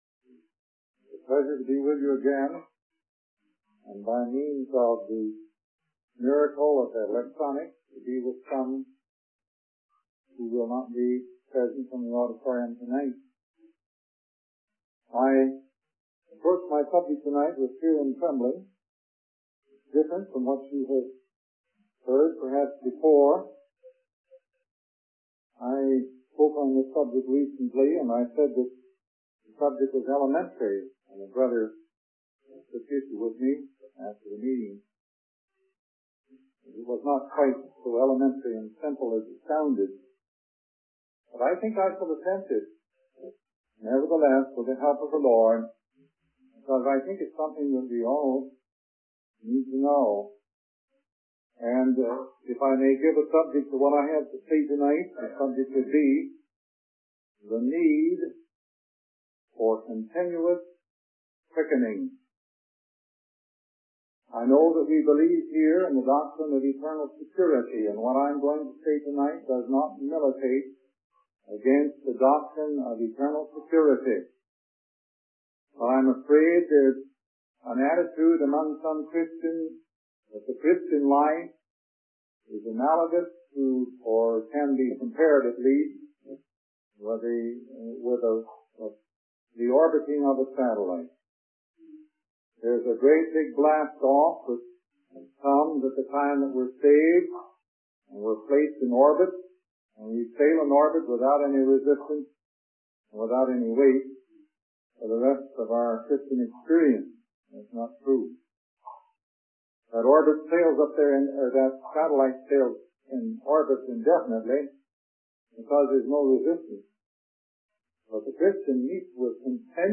In this sermon, the preacher discusses the concept of quickening and the importance of sustaining our spiritual life.